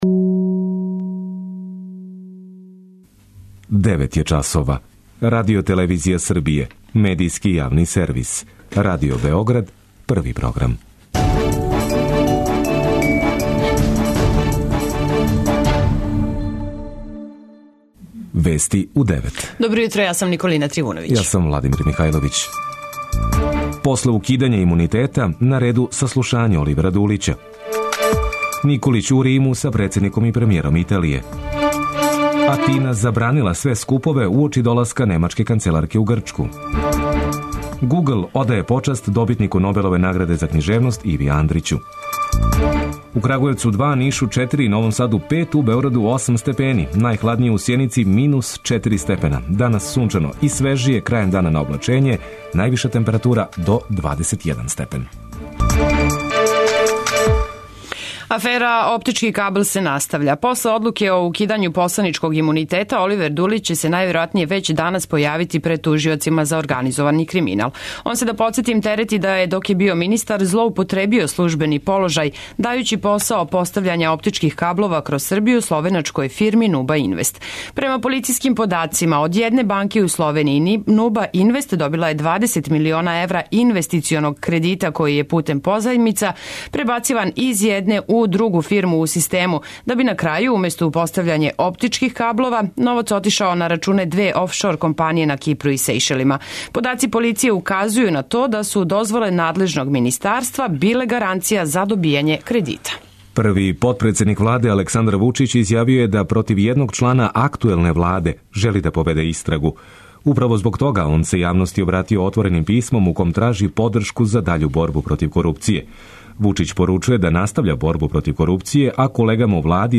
преузми : 9.80 MB Вести у 9 Autor: разни аутори Преглед најважнијиx информација из земље из света.